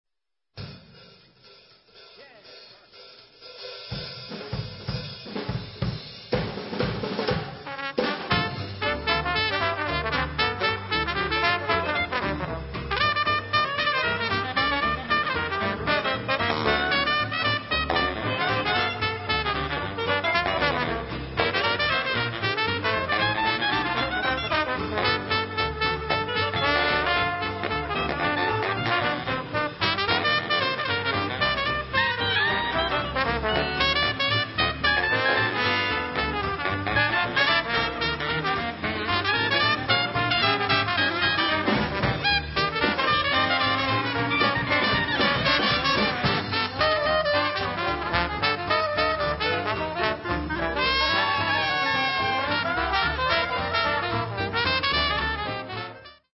Recorded Live at NEWORLEANS JAZZ ASCONA il 5 luglio, 2001